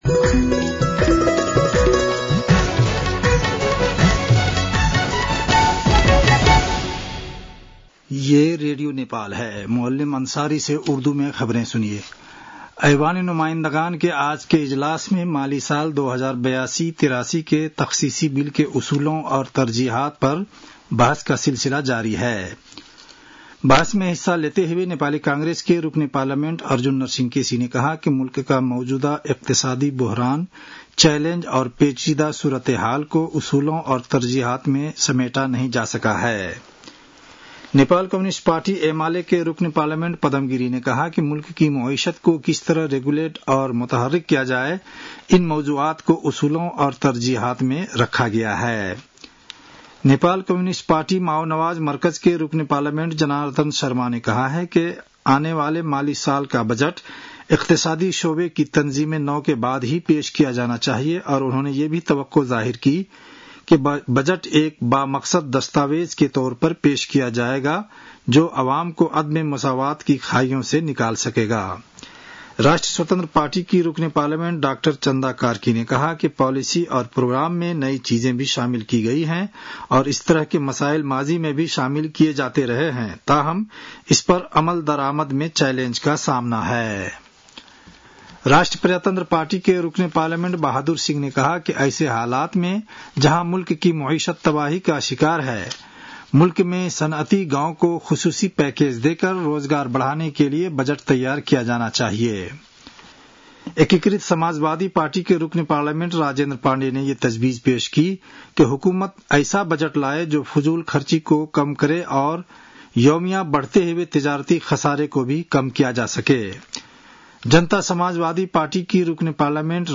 उर्दु भाषामा समाचार : ३१ वैशाख , २०८२